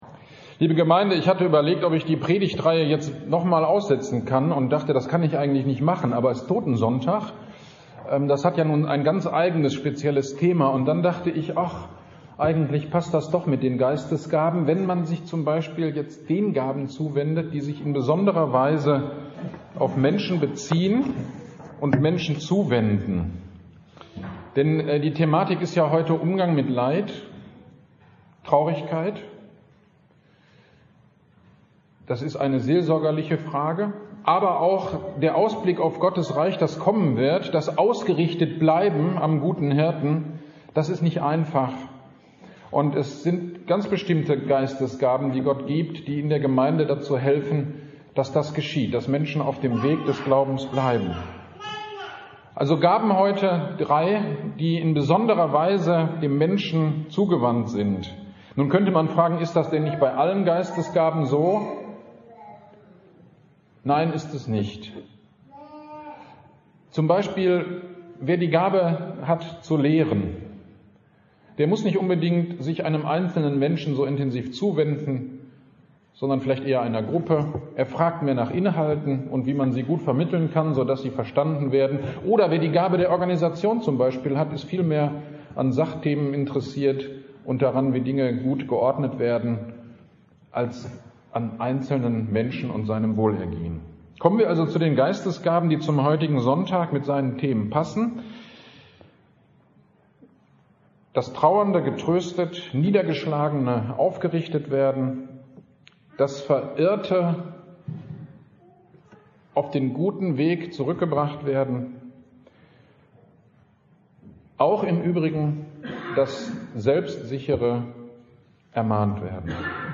Predigtreihe „Gaben und Gemeindebau“ Nr. 4